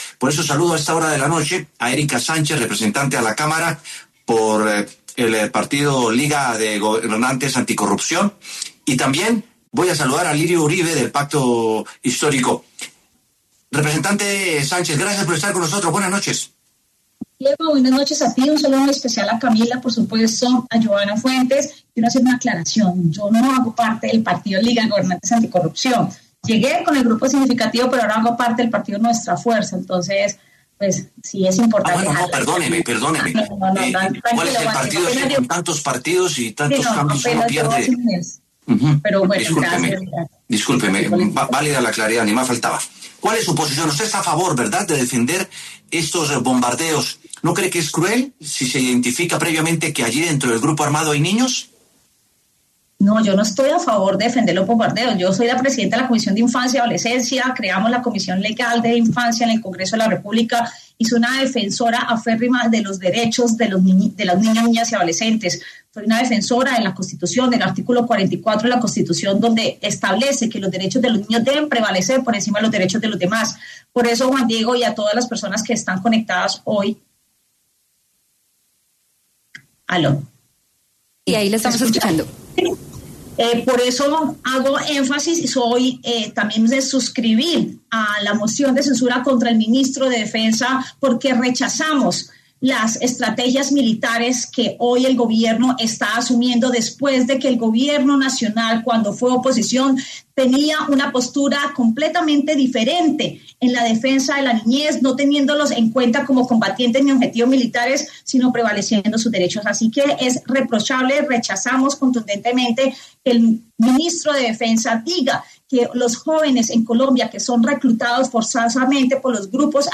Debate: ¿El ministro de Defensa debería salir de su cargo tras bombardeos donde murieron niños?
Los representantes a la Cámara, Erika Sánchez y Alirio Uribe, pasaron por los micrófonos de W Sin Carreta y debatieron la moción de censura que se adelanta contra el ministro de Defensa.